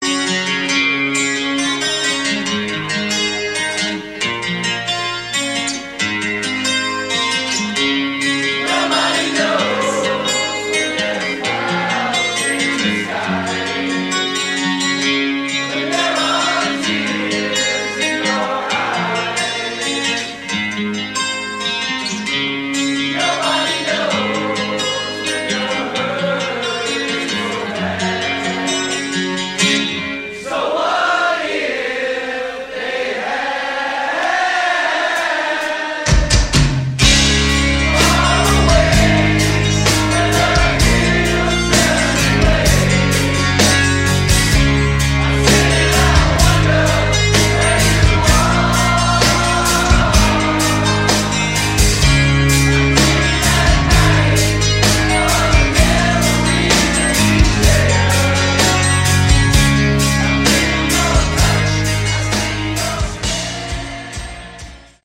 Category: Hard Rock
lead vocals
bass
drums
acoustic guitar, vocals
Crowd Version